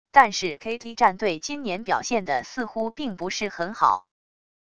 但是KT战队今年表现的似乎并不是很好wav音频生成系统WAV Audio Player